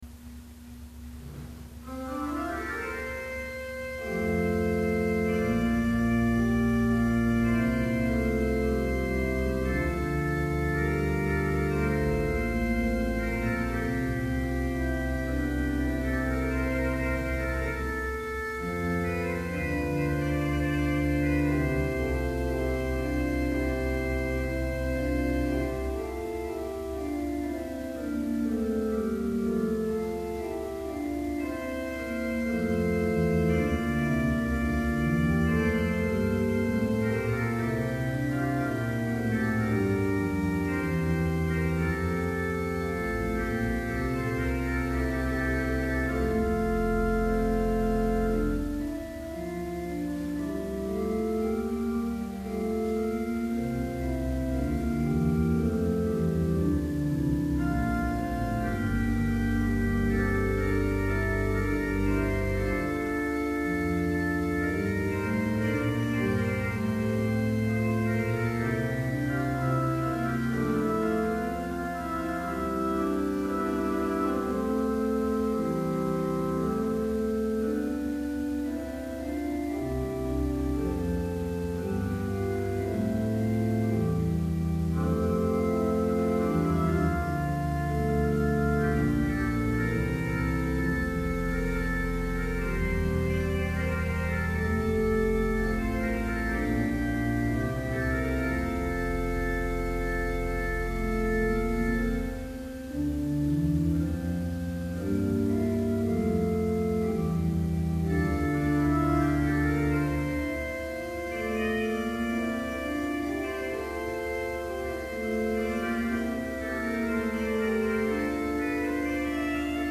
Complete service audio for Summer Chapel - June 13, 2012